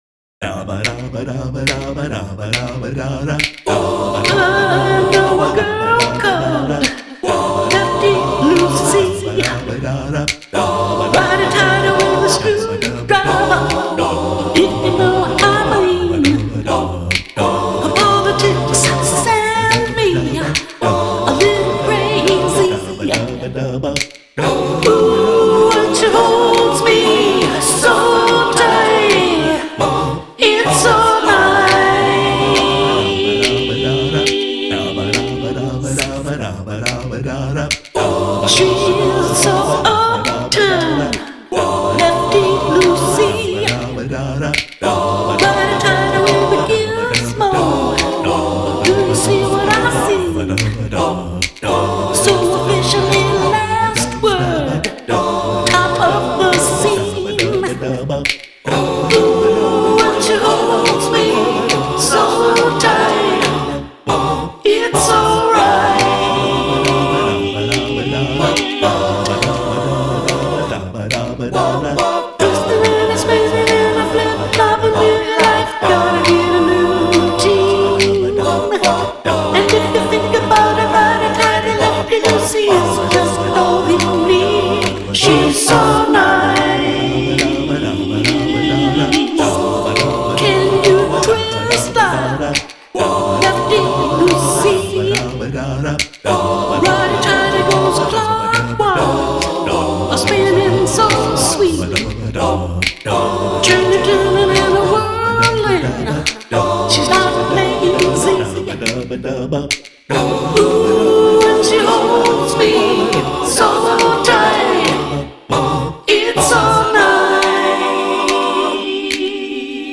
An a cappella tune